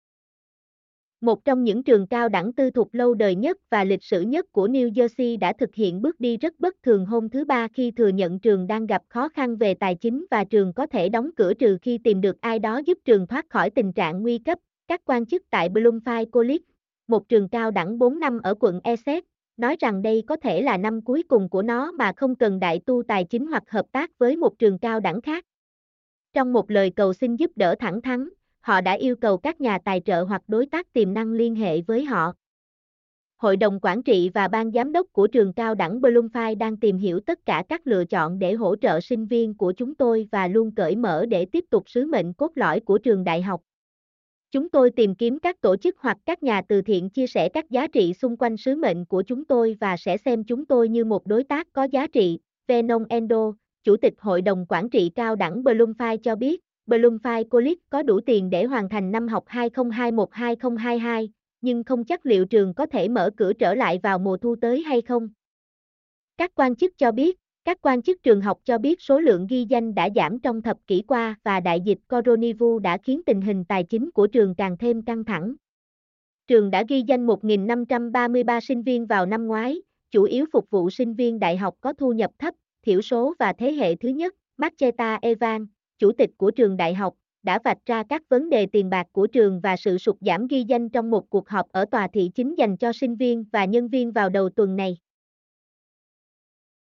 mp3-output-ttsfreedotcom-10.mp3